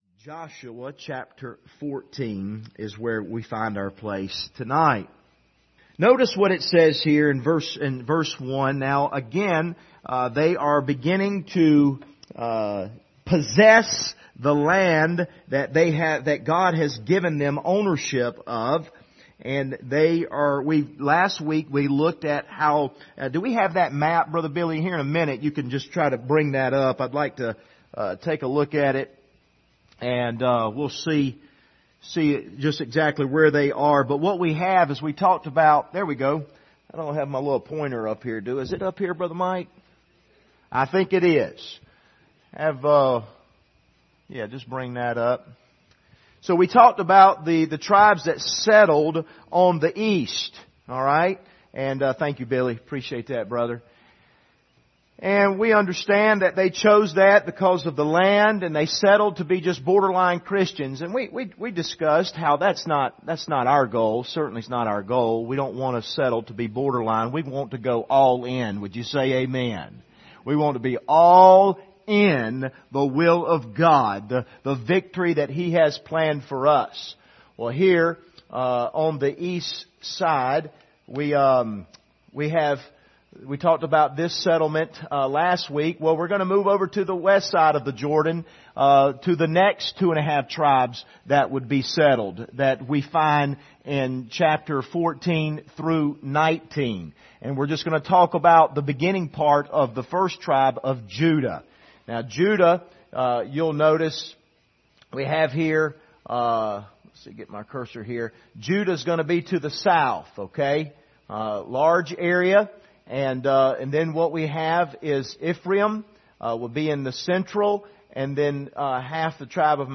Passage: Joshua 14:1-13 Service Type: Sunday Evening